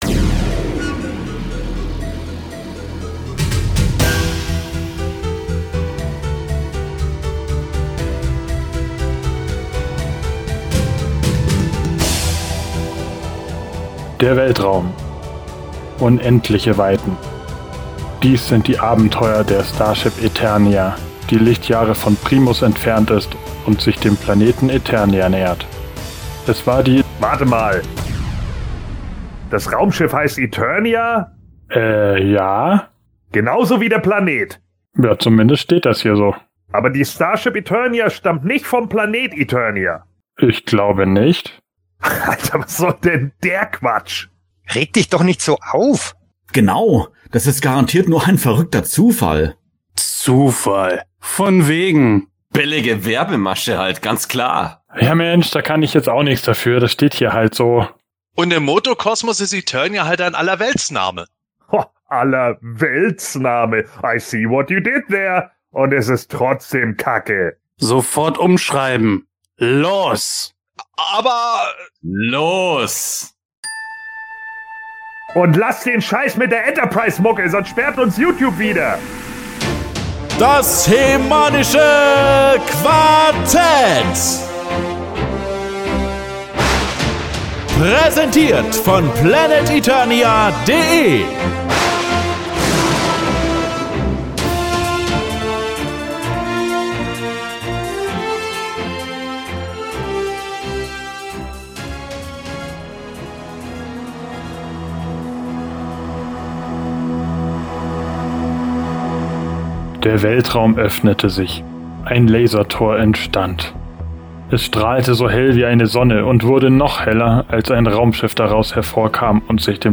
Die neuen Abenteuer des HE-MANischen Quartetts | Das Hörspiel | PlanetEternia ~ Das HE-MANische Quartett Podcast
Masters im Weltall! In der "New Adventures"-Persiflage enthüllt das HE-MANische Quartett, dass für He-Man und Skeletor auch außerhalb von Eternia nicht alles rund läuft. Verkalkte Waffenmeister, verwirrte Mutanten, gnadenlose Schleichwerbung und vieles mehr erwarten euch in unserem Comedy-Hörspiel....